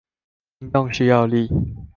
Голоса - Тайваньский 446